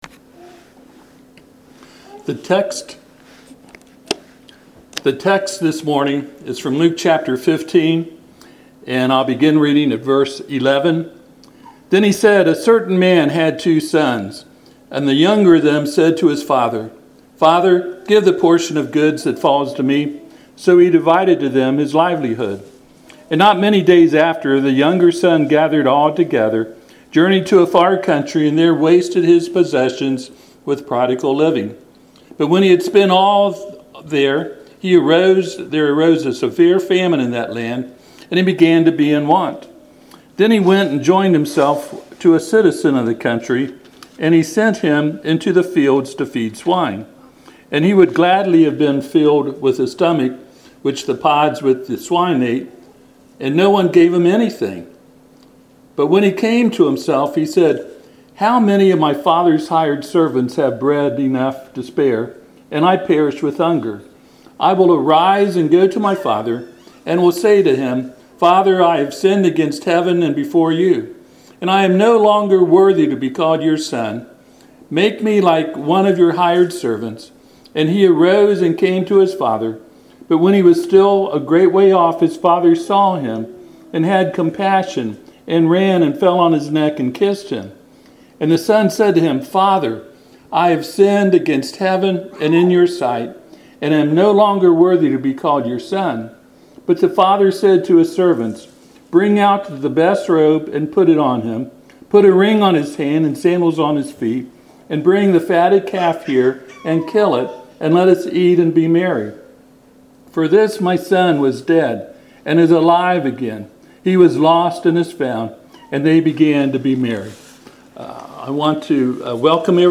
Passage: Luke 15:17-20 Service Type: Sunday AM